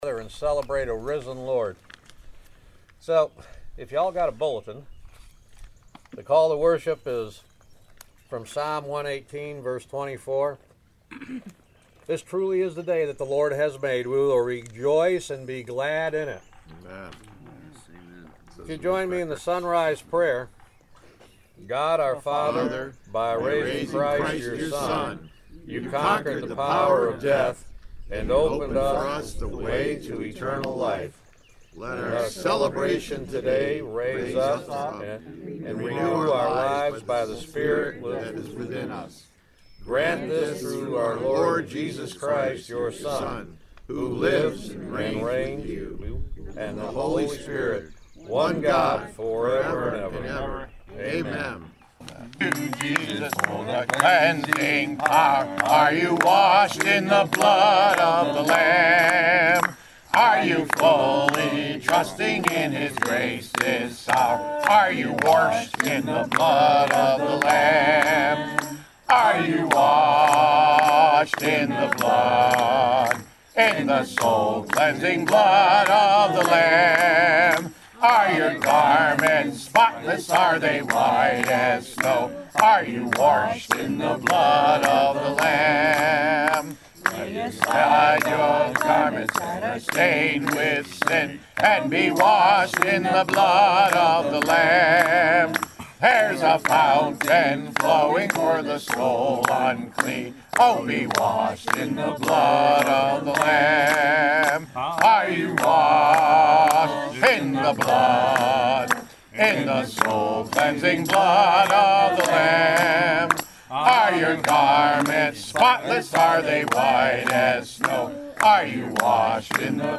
April 16th, 2017 Easter Sunday Sunrise Service Podcast
WBC-4-16-17-easter-sunrise.mp3